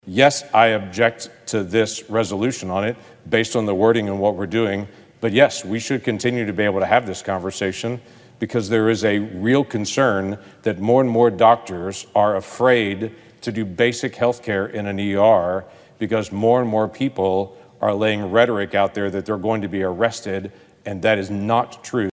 Oklahoma Senator James Lankford took to the floor on Tuesday and spoke out in opposition of the democrats attempting to force an abortion vote through the Senate.